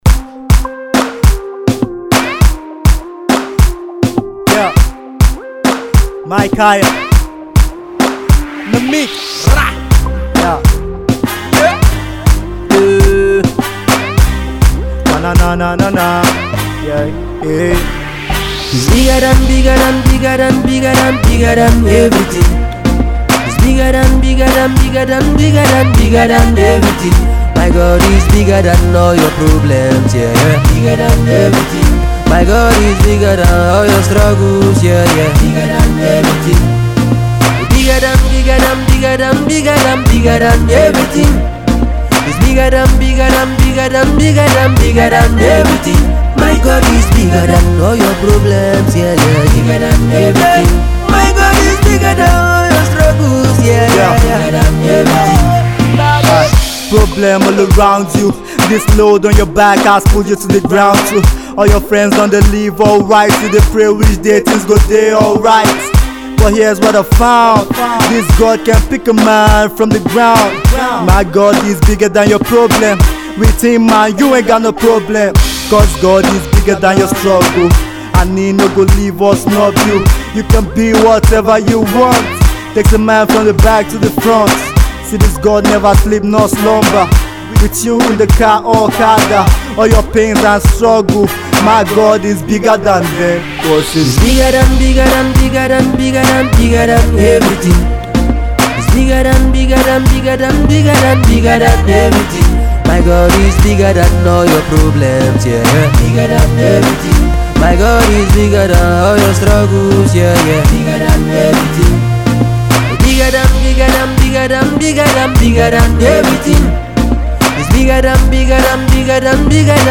crooner